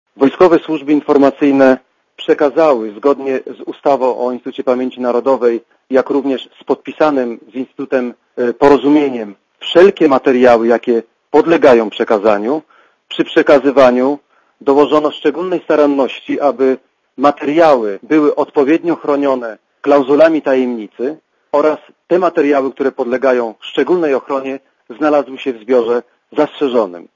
Nie jest tak źle, jak napisała Trybuna - mówi Radiu ZET generał Marek Dukaczewski, szef Wojskowych Służb Informacyjnych.